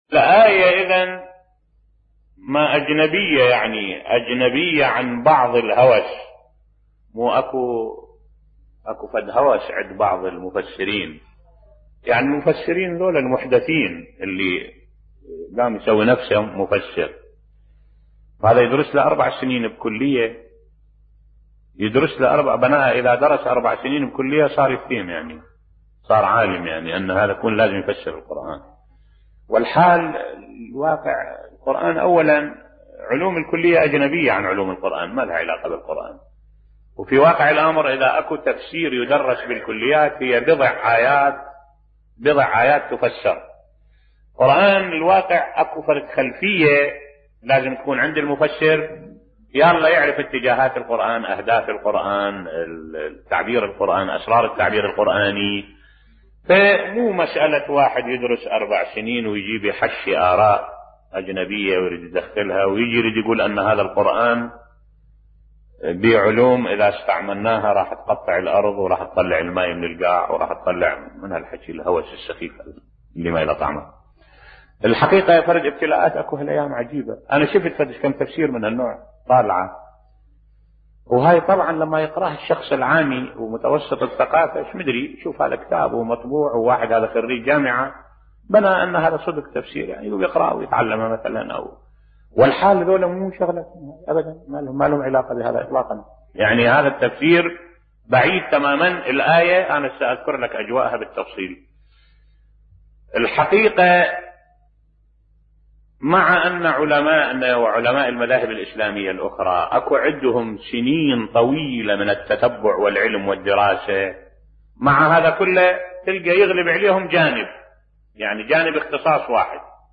ملف صوتی خلفية مفسر القرآن تفرض عليه في تفسيره بصوت الشيخ الدكتور أحمد الوائلي